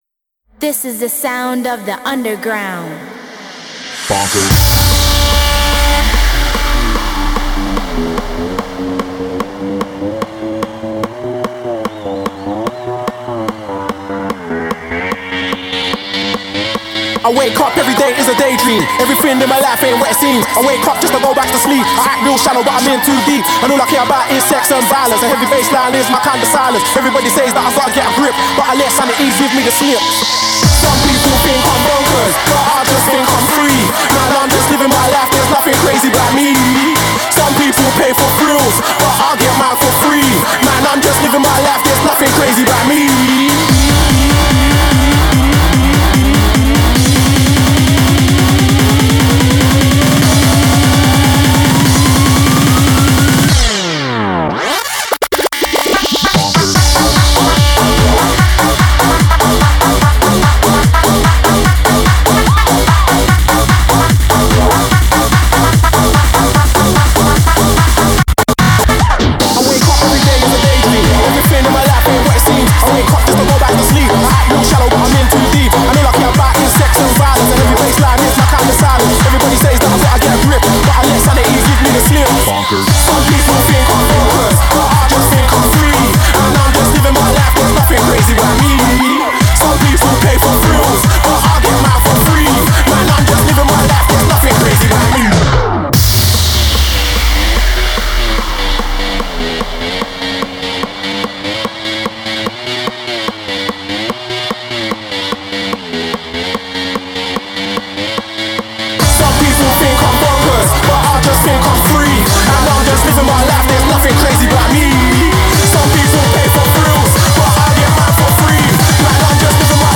Категория: Shuffle